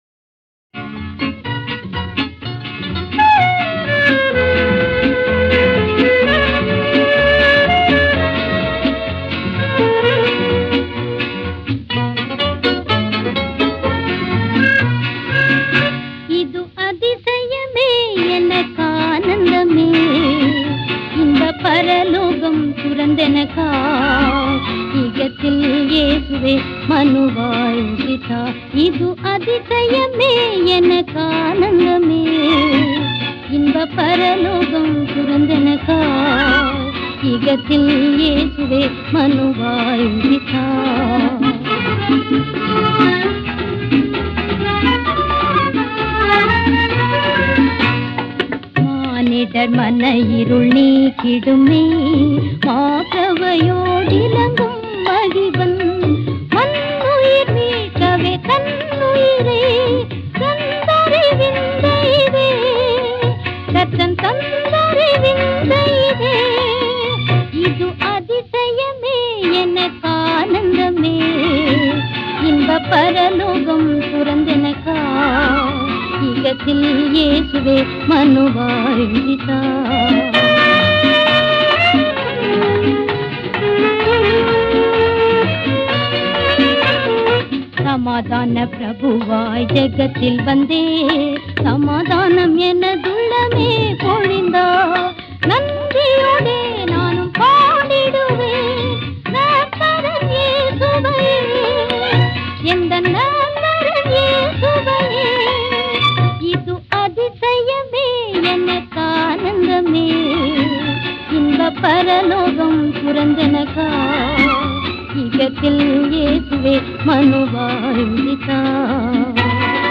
Royalty free Christian music.